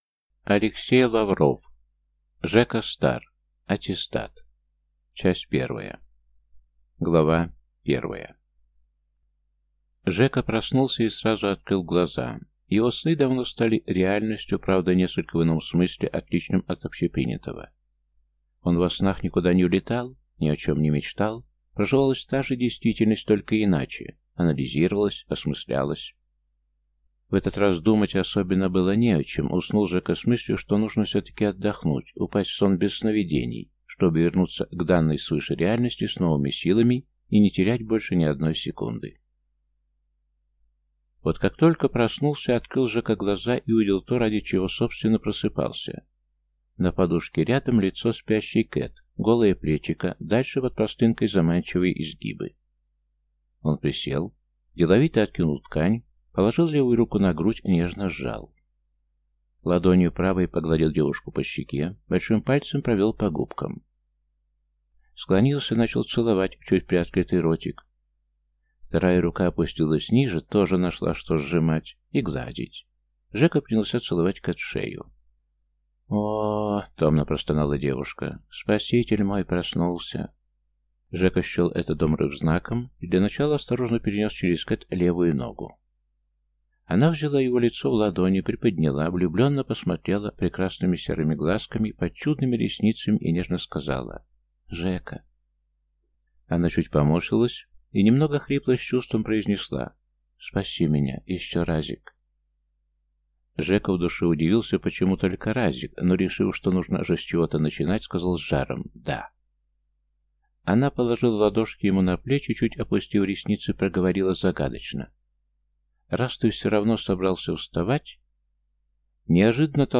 Аудиокнига Жека Стар. Аттестат | Библиотека аудиокниг